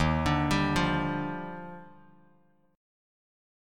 D#M9 Chord